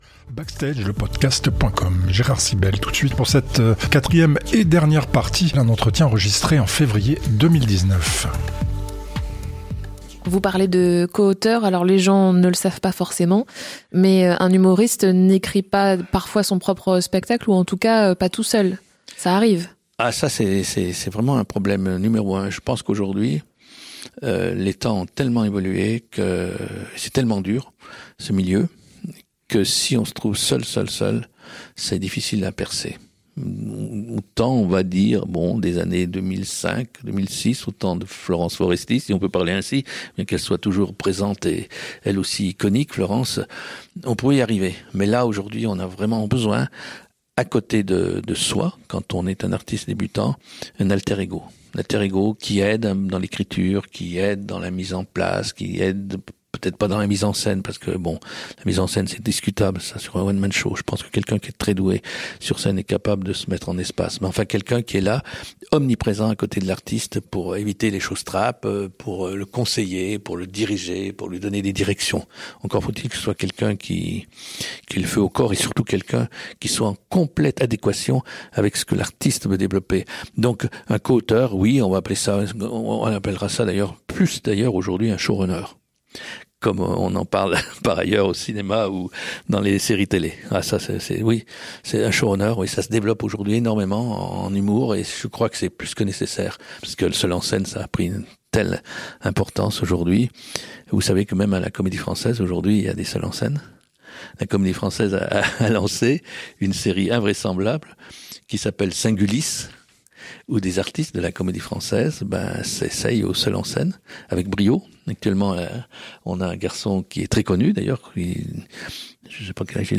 Entretien enregistré en février 2019.